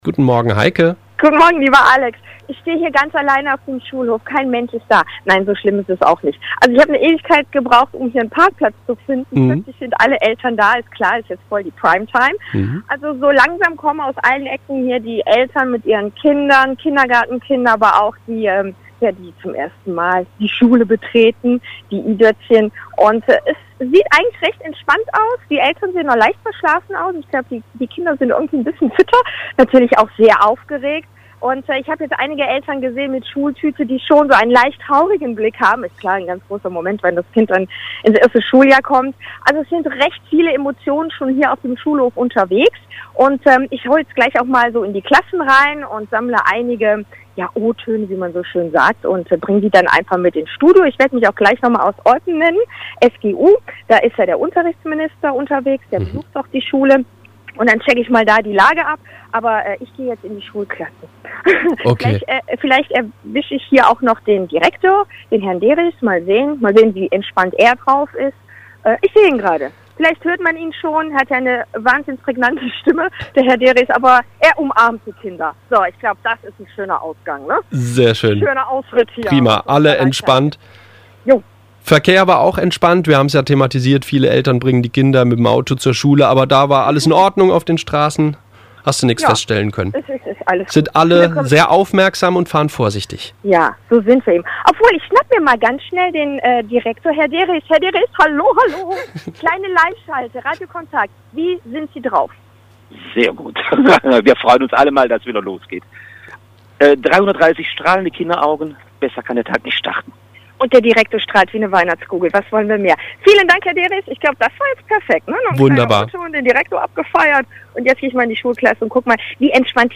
WakeUp live